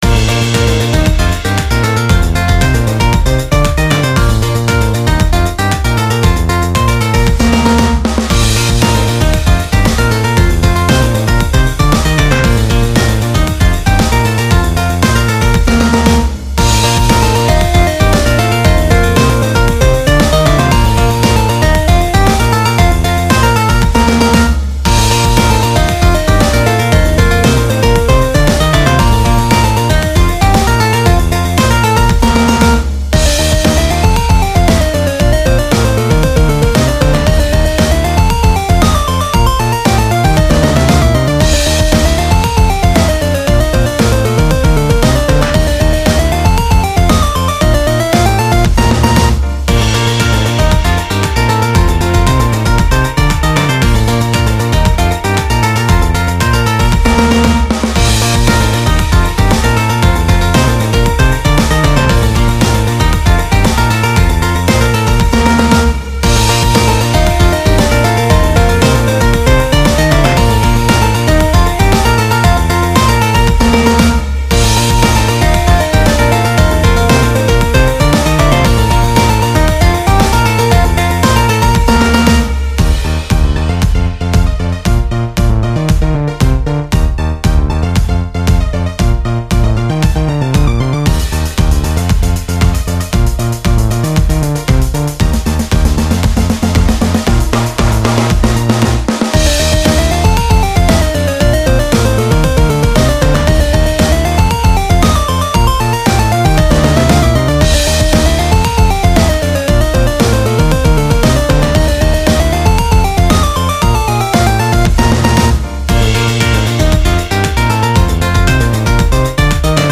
コメディ&アニメ&コミカル系ＢＧＭ
【用途/イメージ】　ハッピー　うれしい　明るい　楽しい　パーティー　アニメ　チャレンジ　スポーツ　etc